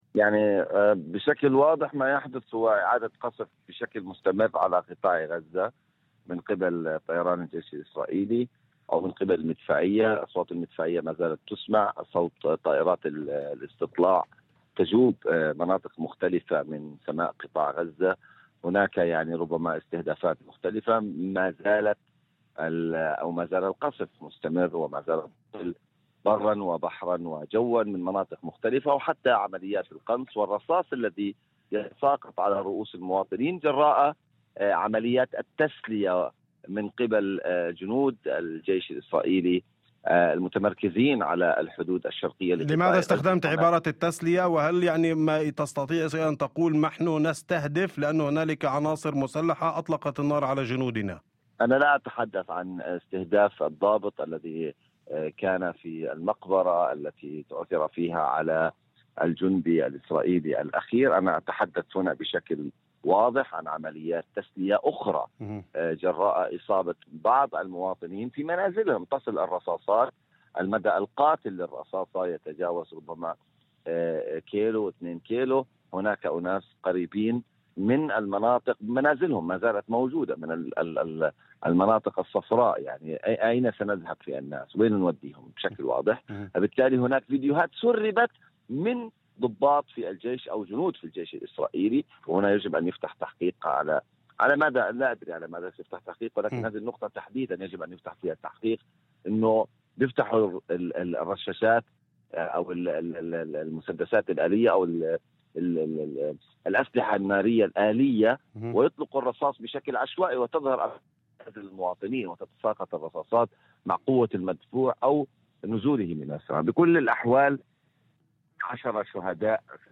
لبرنامج "أول خبر" على إذاعة الشمس